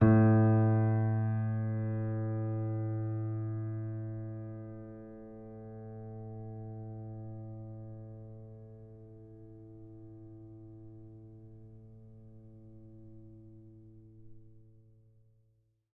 sampler example using salamander grand piano
A2.ogg